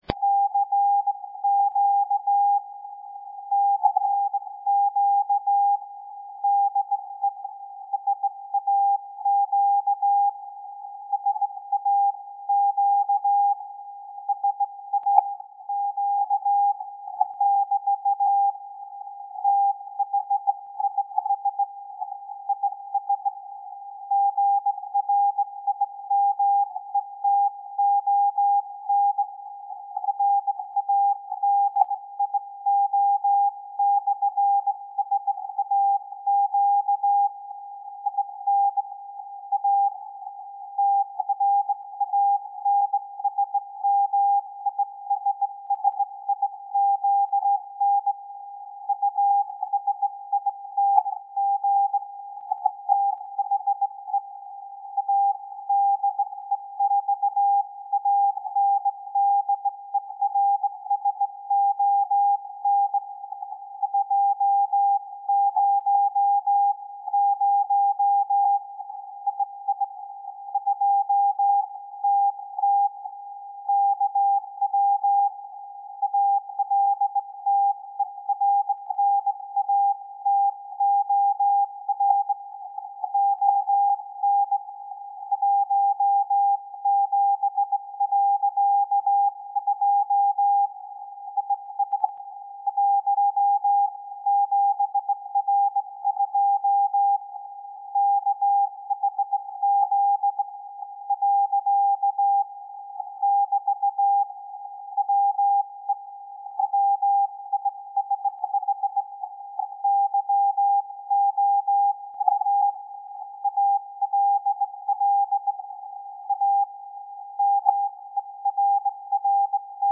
With the A.D.A. the signal was very strong,